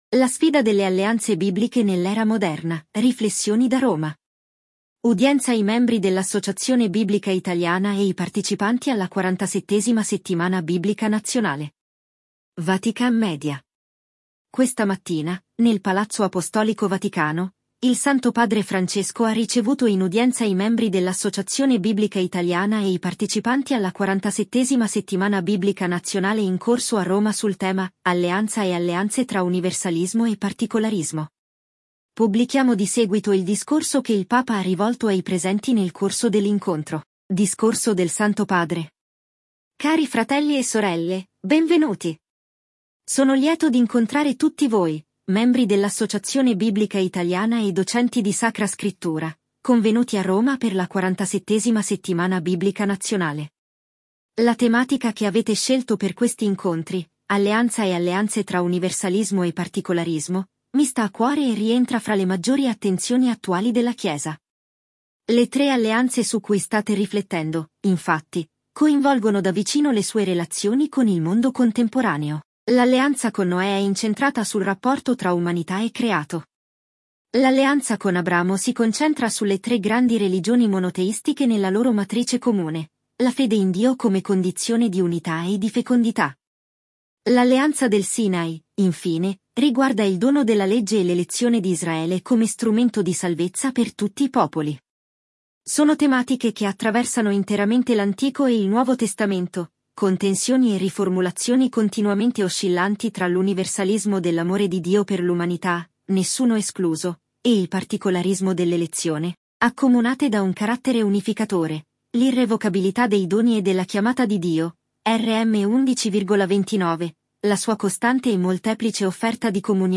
Udienza i Membri dell’Associazione Biblica Italiana e i partecipanti alla XLVII Settimana biblica nazionale
Discorso del Santo Padre